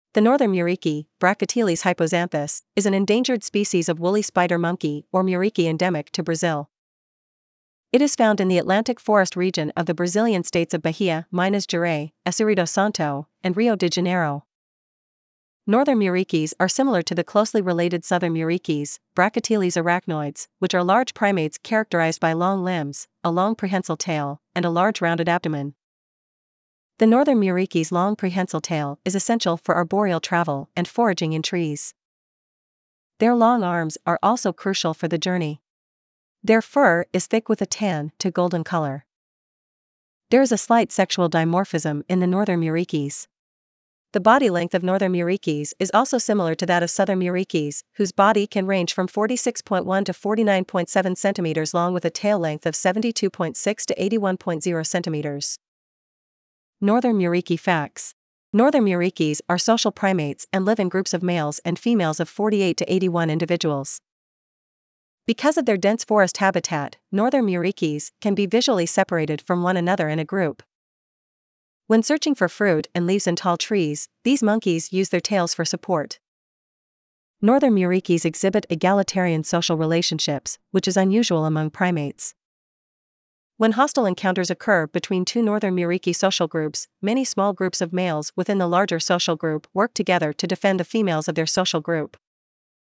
Northern Muriqui
Northern-Muriqui.mp3